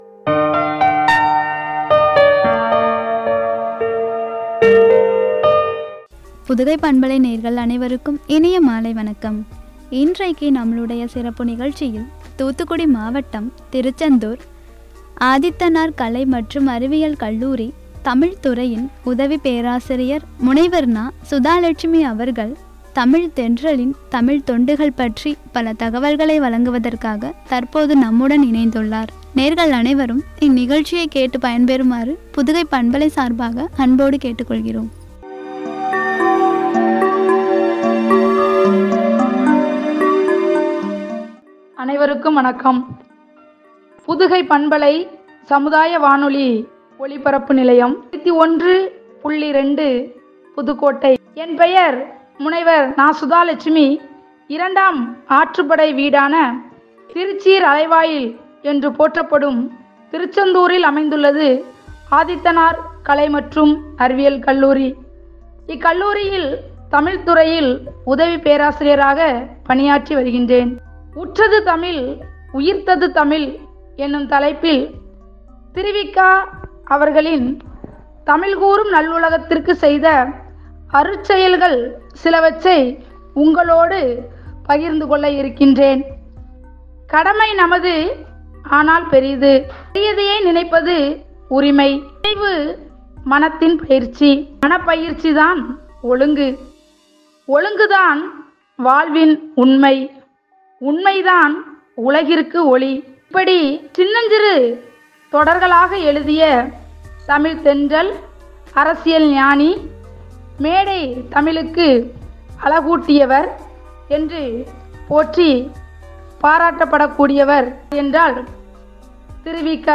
எனும் தலைப்பில் வழங்கிய உரை.